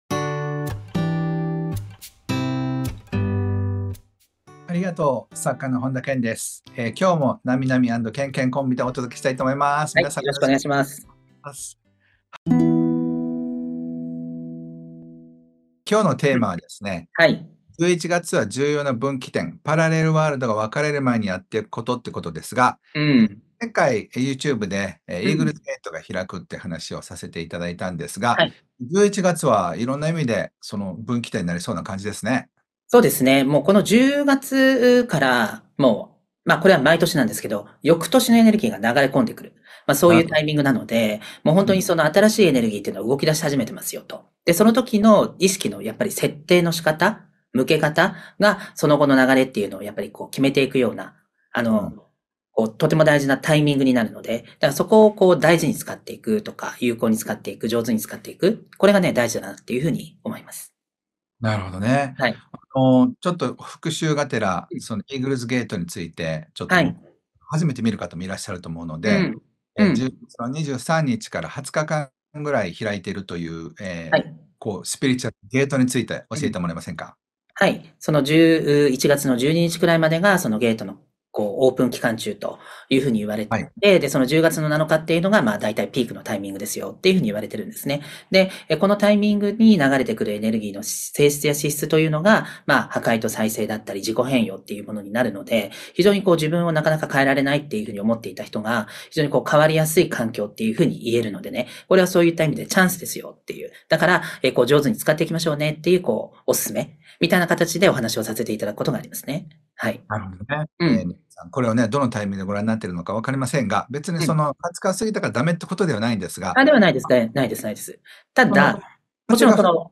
パラレルワールドが分かれる前にやっておくこと 並木良和・本田健 対談 - 本田健の人生相談 〜Dear Ken〜